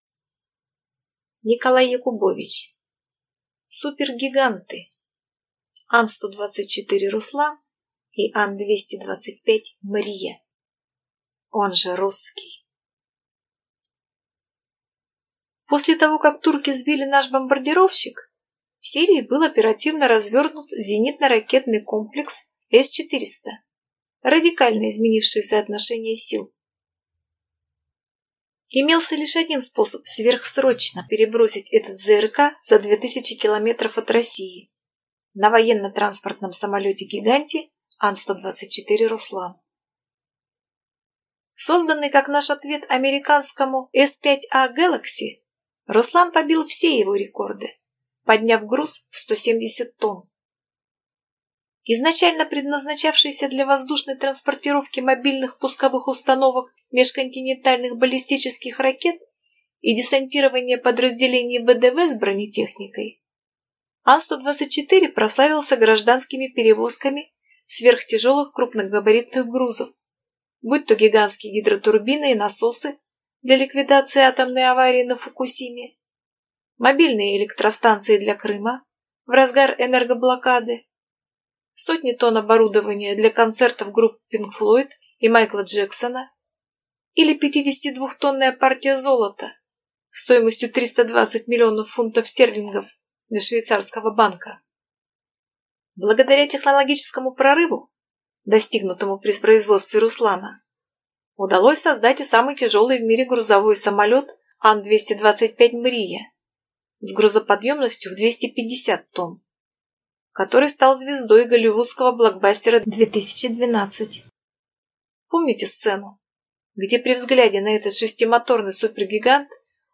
Аудиокнига Супергиганты Ан-124 «Руслан» и Ан-225 «Мрия». «Он же русский!»